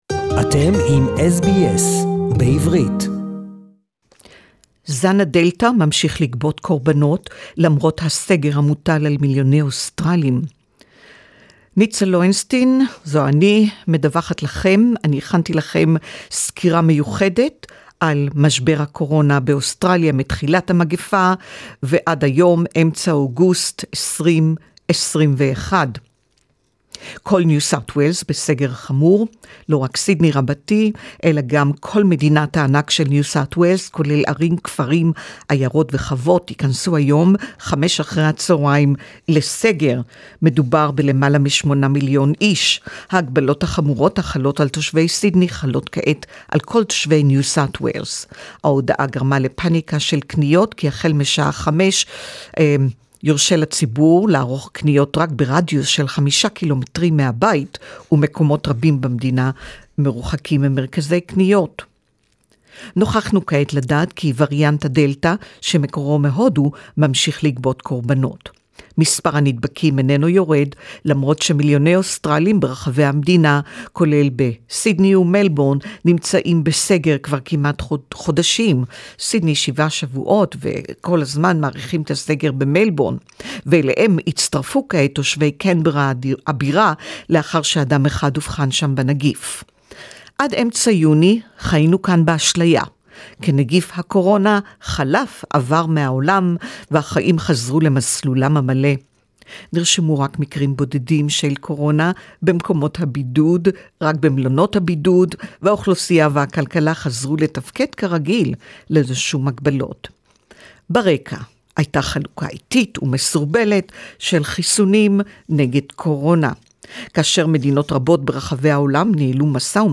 “This is literally a war" The battle of Australia against Covid-19 (a special report in Hebrew)